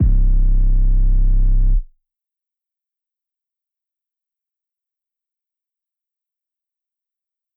808s
REDD 808 (20).wav